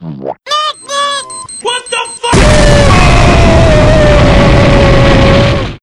mvm_tank_deploy.wav